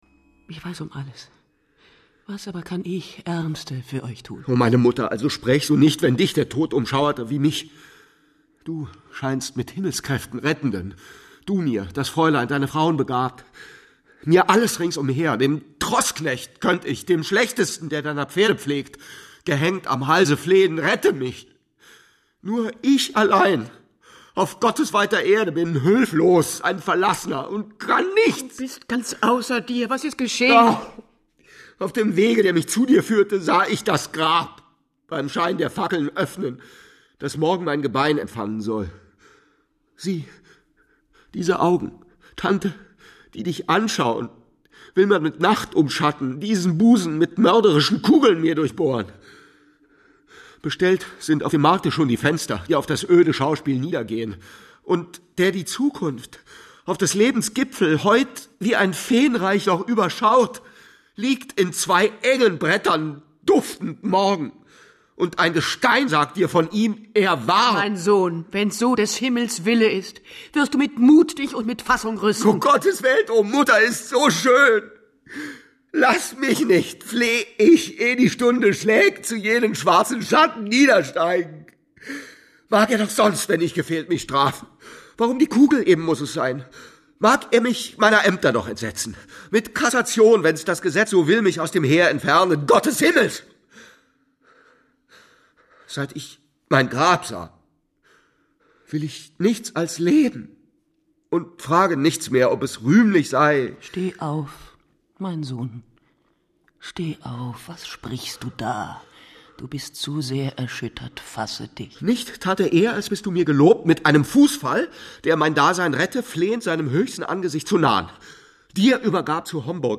Peter Fitz, Corinna Kirchhoff, Lavinia Wilson, Ulrich Matthes (Sprecher)
Schlagworte Drama • Fehrbellin • Gefecht • Handschuh • Hörbuch; Hörspiel • Hörbuch; Hörspiele (Audio-CDs) • Hörbuch; Klassiker • Hörbuch; Klassiker (Audio-CDs) • Hörspiele (Audio-CDs) • Klassiker • Klassiker (Audio-CDs) • Prinzessin Natalie